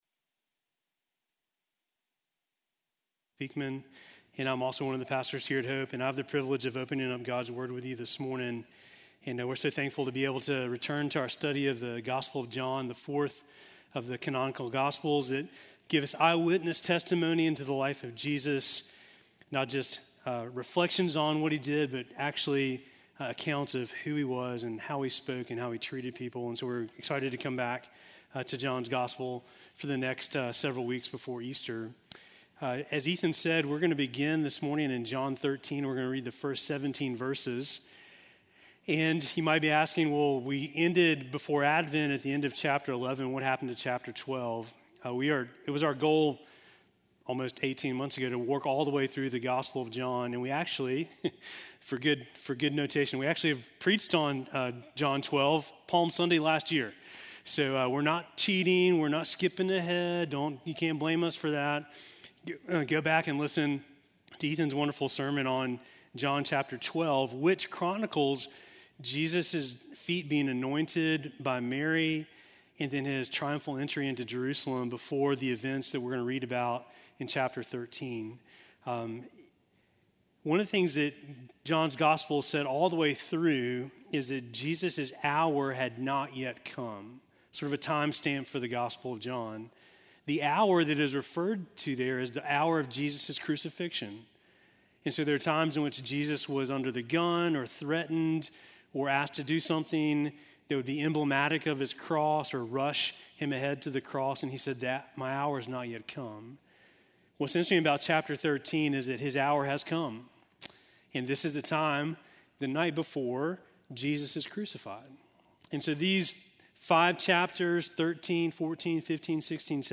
Sermon from January 11